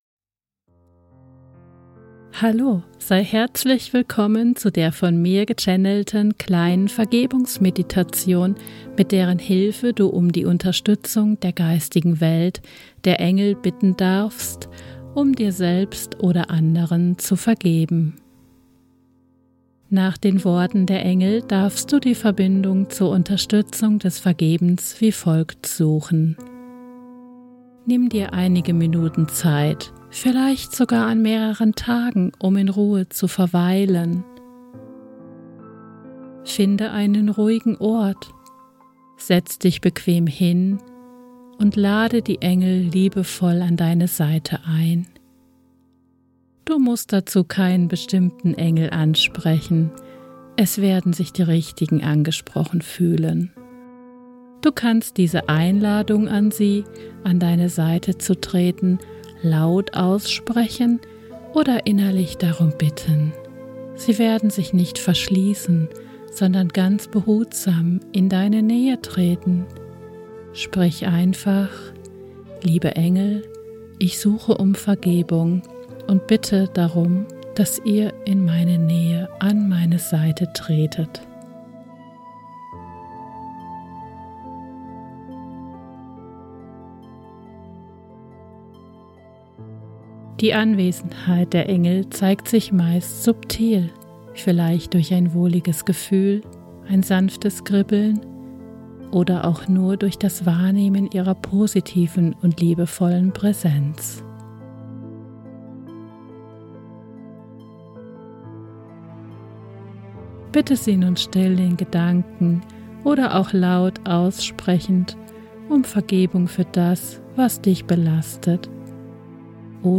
Meditation
Die Musik, die während der Meditation verwendet wird, ist urheberrechtlich geschützt und stammt von Buddha Code.